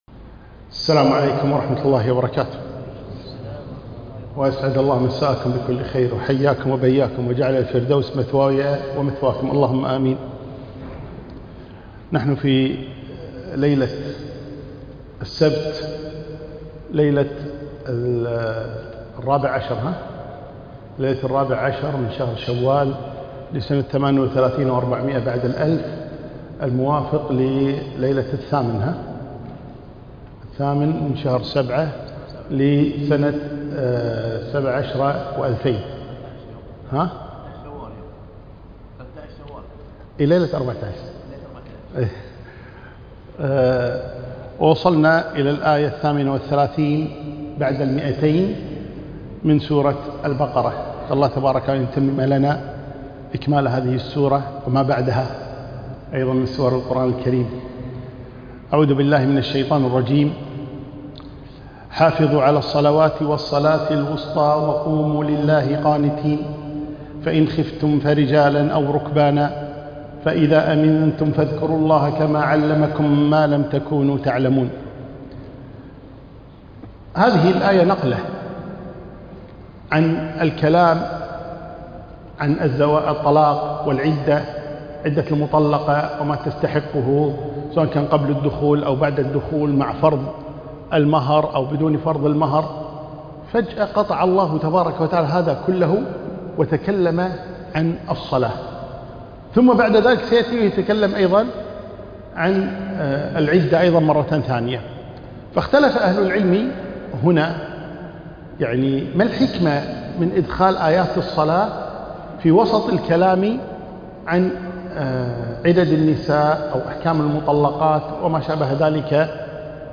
كلمة لقاء الجمعة